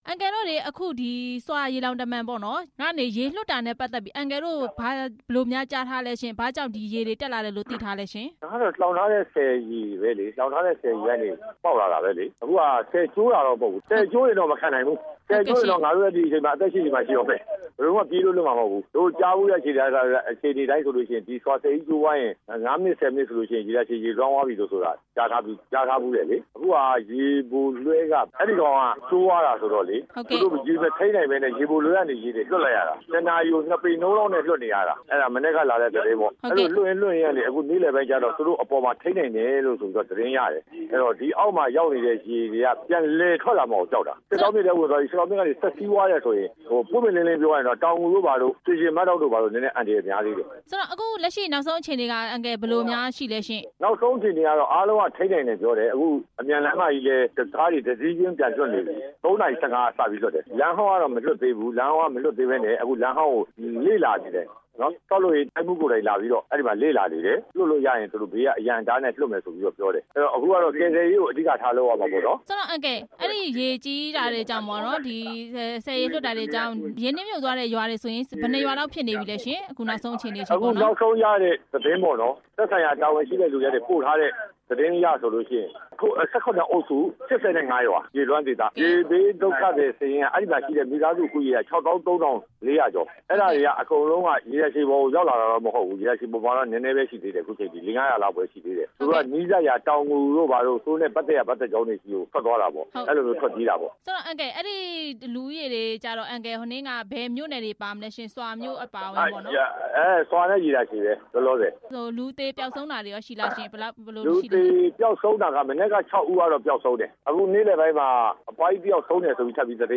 ဆွာရေလှောင်တမံ ရေလွှတ် တာကြောင့် ရေနစ်မြုပ်နေမှုအကြောင်း မေးမြန်းချက်